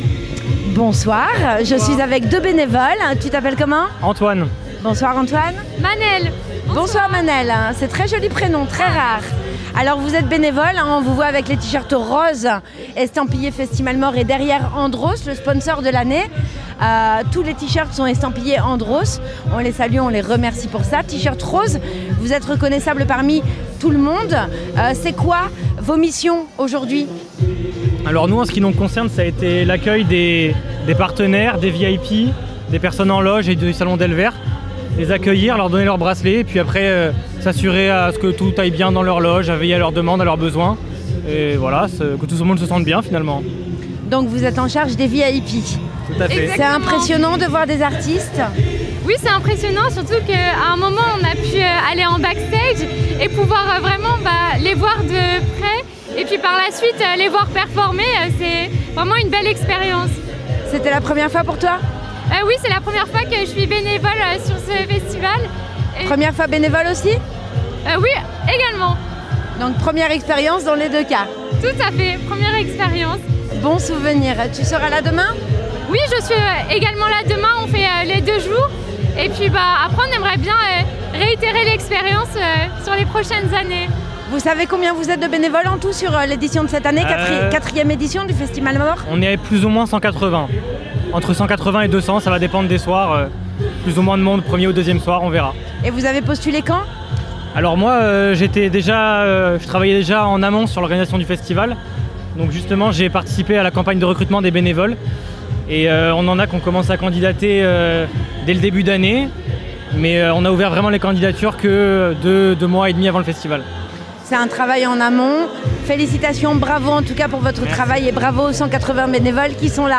Interviews Festi'malemort email Rate it 1 2 3 4 5